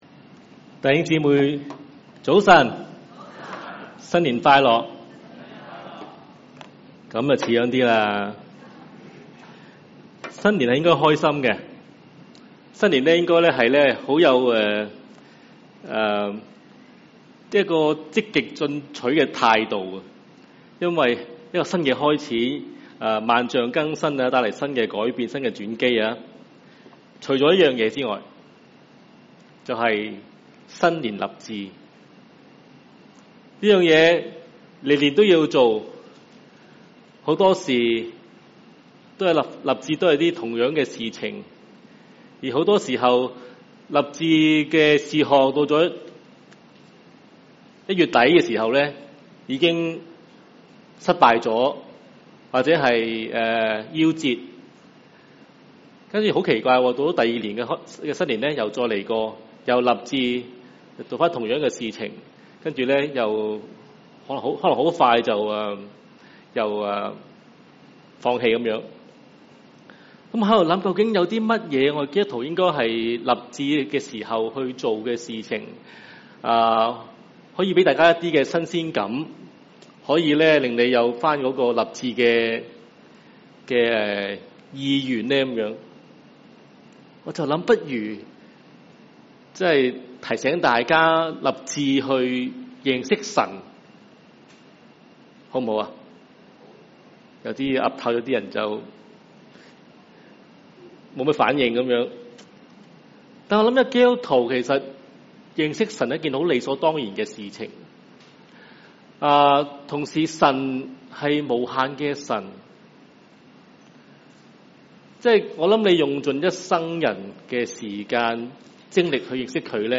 Cantonese 3rd Service, Chinese Category